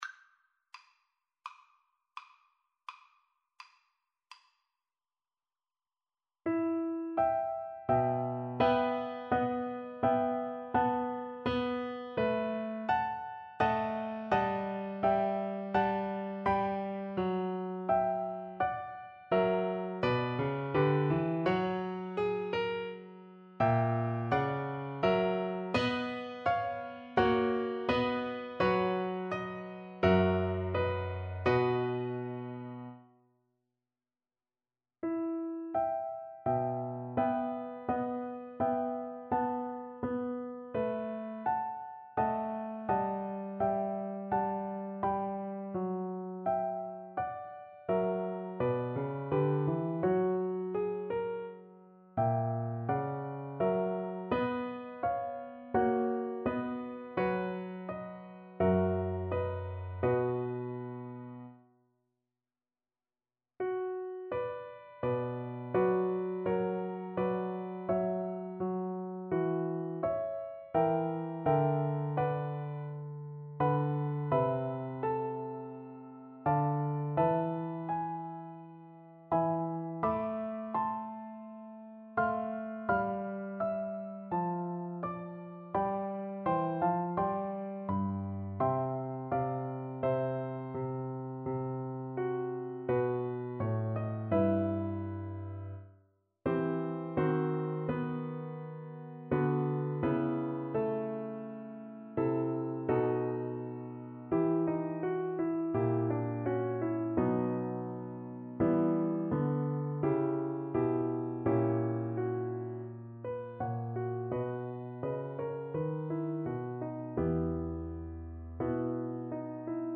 Classical Vivaldi, Antonio Sonata No. 5 in E Minor, Op. 14, First Movement Cello version
Cello
E minor (Sounding Pitch) (View more E minor Music for Cello )
Largo =42
4/4 (View more 4/4 Music)
Classical (View more Classical Cello Music)